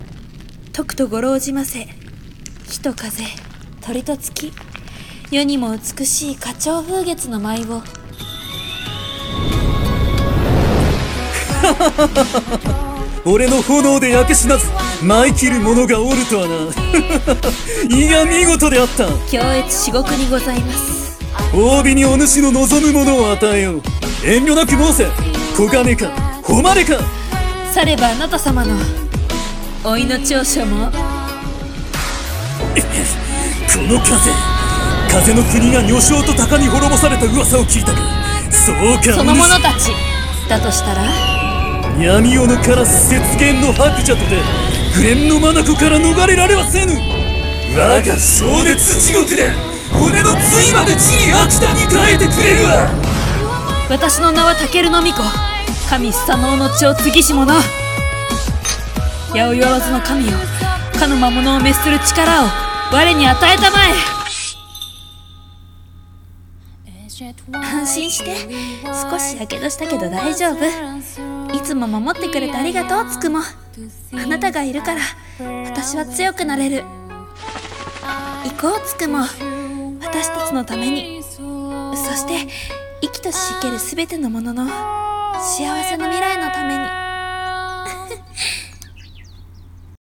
声劇】古の白の物語~火鳥風月ﾉ章